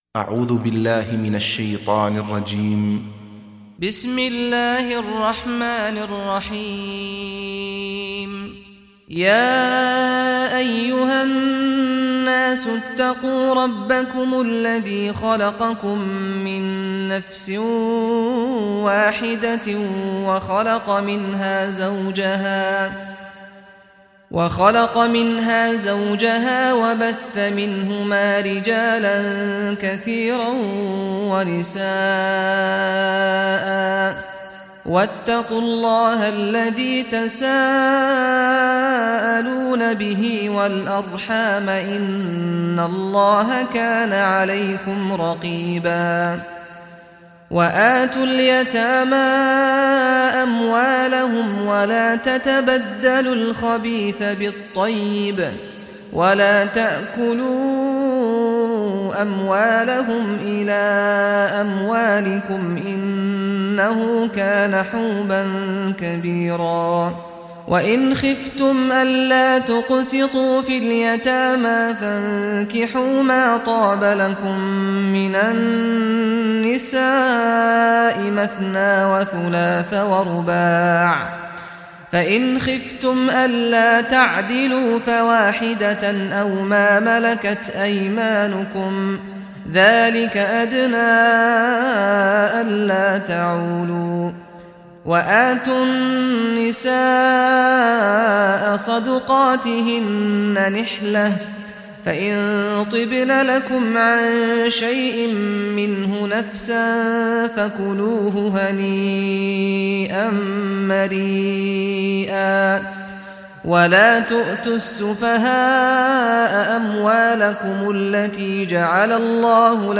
Surah Nisa Recitation by Sheikh Saad Al Ghamdi
Surah Nisa, listen online mp3 tilawat / recitation in Arabic in the beautiful voice of Sheikh Saad Al Ghamdi.